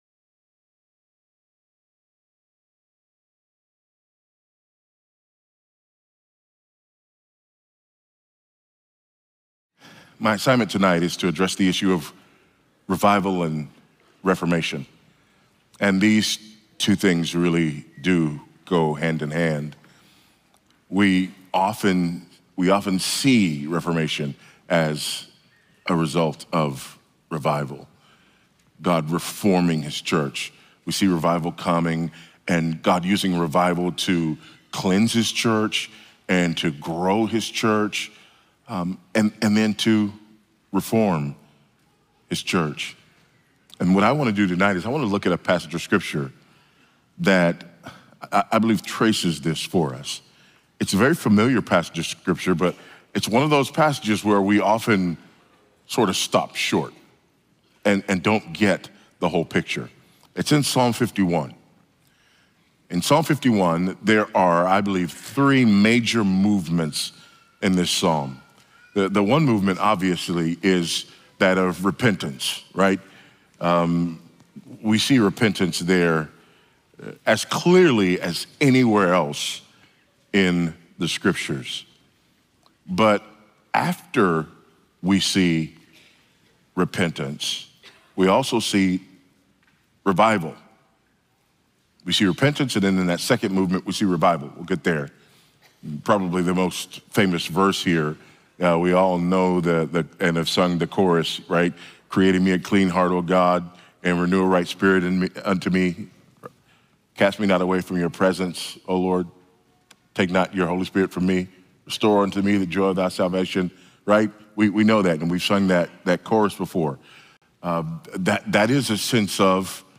2025 at the "Revive Us, O Lord" 2025 National Founders Conference in Cape Coral, Florida.
Sermons